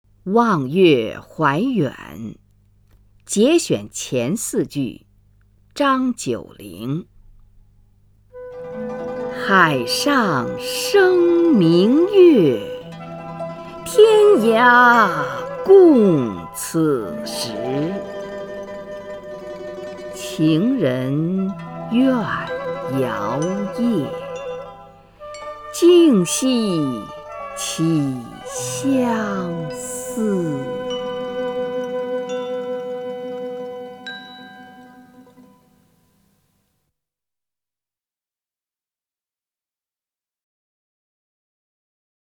虹云朗诵：《望月怀远（节选）》(（唐）张九龄) （唐）张九龄 名家朗诵欣赏虹云 语文PLUS
（唐）张九龄 文选 （唐）张九龄： 虹云朗诵：《望月怀远（节选）》(（唐）张九龄) / 名家朗诵欣赏 虹云